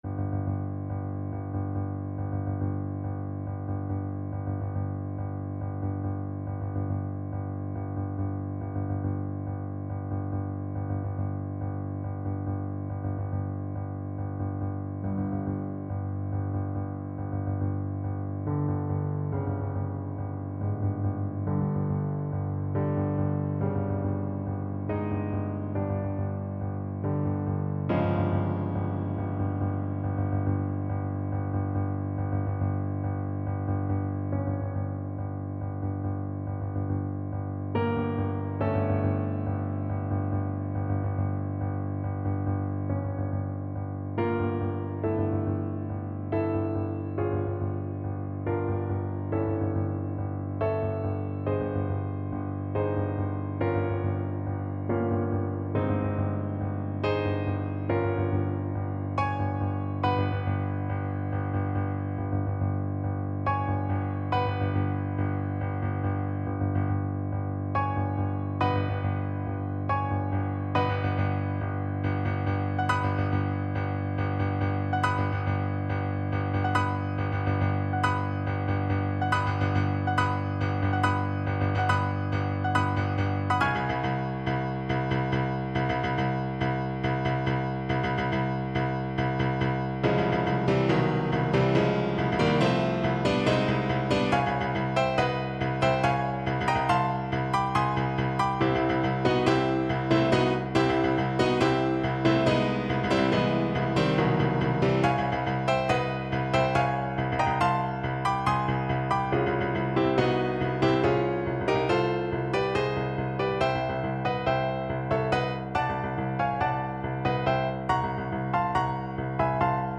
Play (or use space bar on your keyboard) Pause Music Playalong - Piano Accompaniment Playalong Band Accompaniment not yet available reset tempo print settings full screen
5/4 (View more 5/4 Music)
Allegro = 140 (View more music marked Allegro)
Classical (View more Classical Tuba Music)